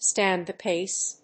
アクセントstànd [stày] the páce